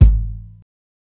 ILLMD003_KICK_IBANGA.wav